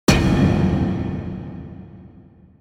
impact-3.ogg